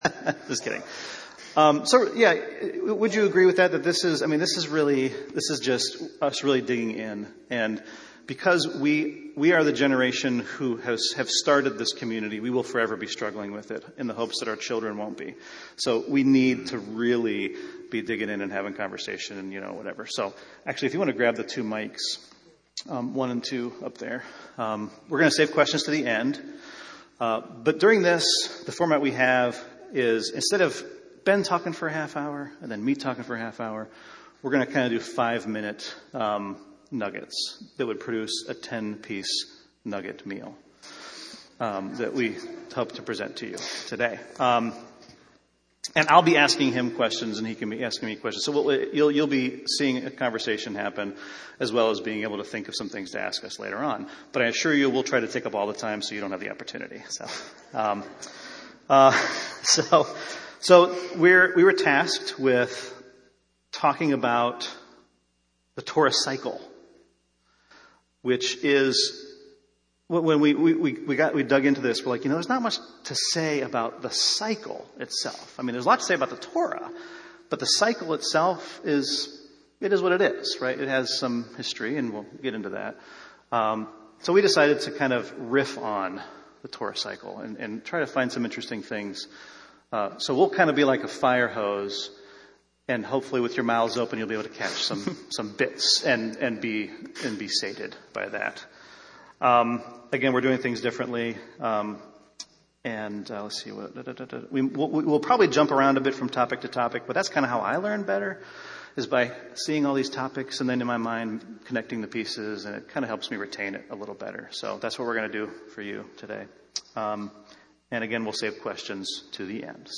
This week’s teaching takes the form of a discussion between two of our members on the topic of the Torah Cycle.